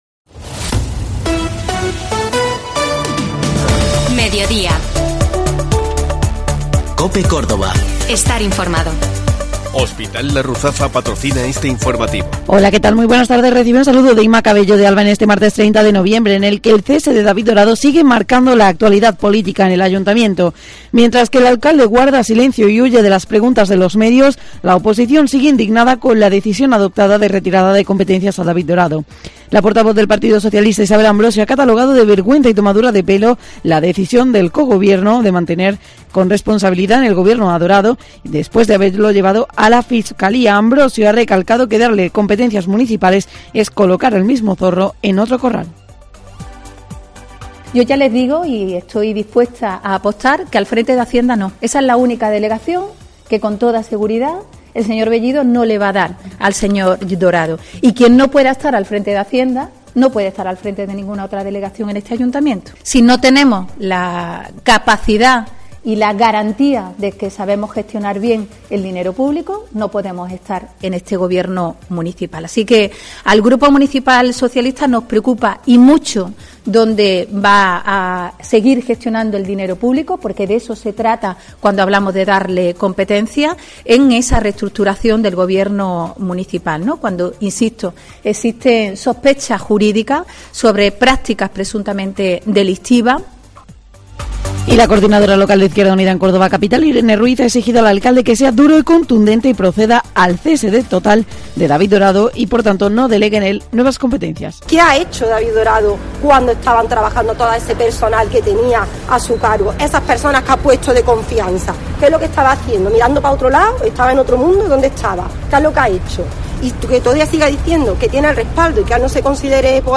Un repaso diario a la actualidad y a los temas que te preocupan.